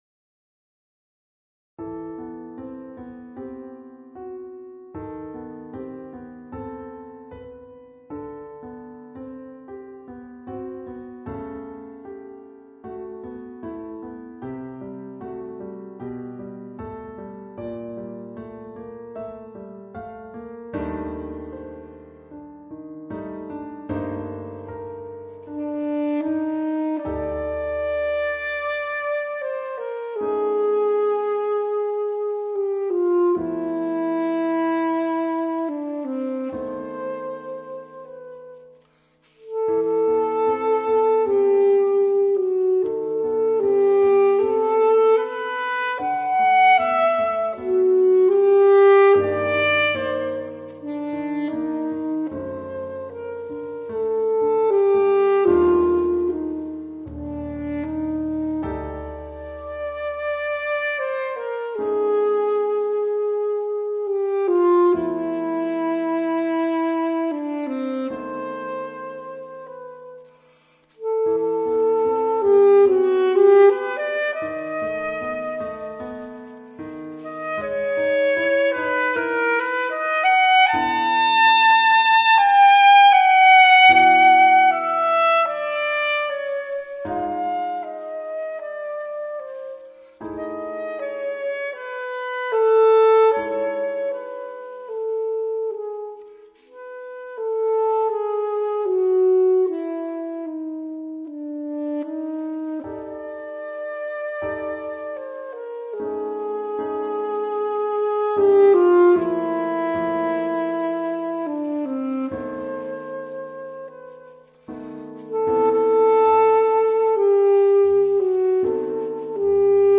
4 beautiful melodic pieces for Saxophone and Piano
Alto & Piano